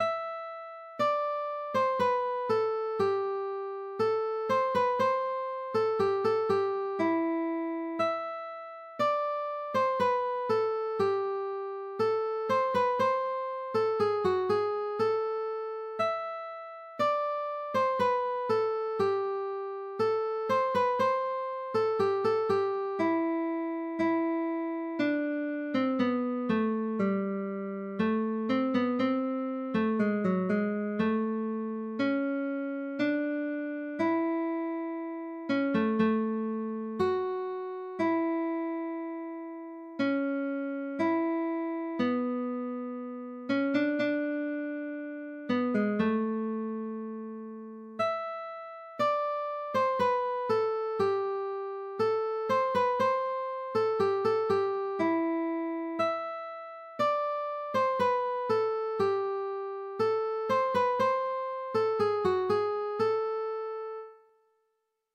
نت گیتار
• سطح نت : مبتدی